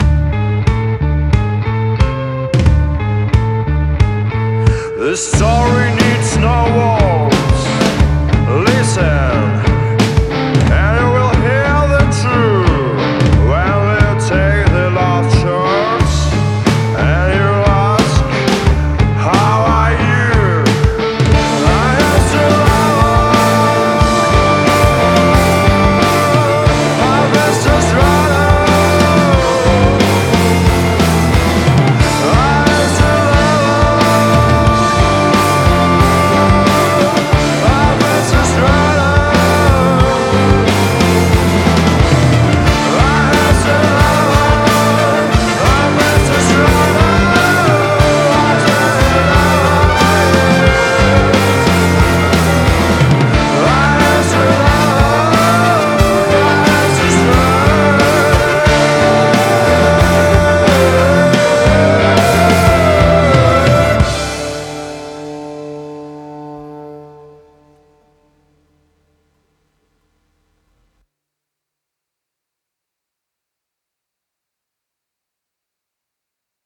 experimental rock? Хиппирок?
На момент записи ребята были "виртуозы" владения инструментами и голосом, поэтому барабаны записали за них и их друг записал валторны и трубу и. Все гитары были записаны без даблов, поэтому пришлось в некоторых местах дописать самому то же самое, потому что ребята очень щепетильно относятся к собственным аранжировкам.